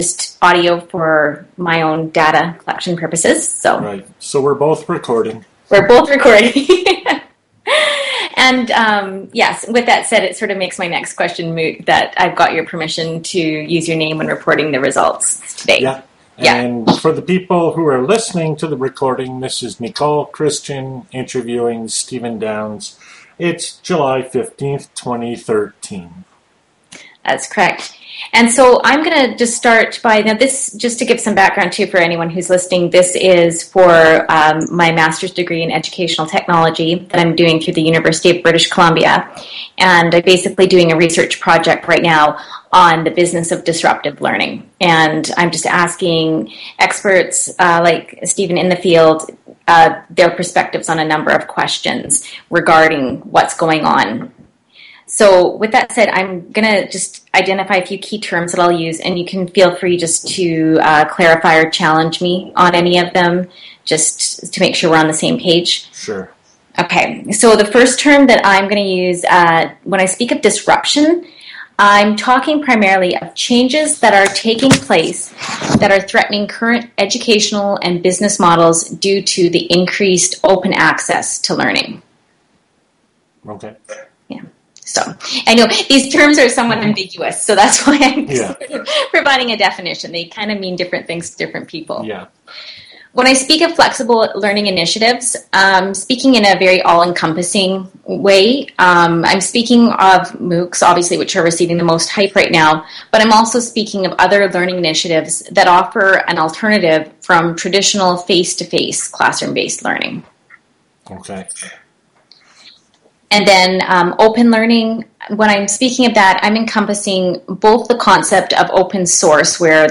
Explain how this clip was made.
Skype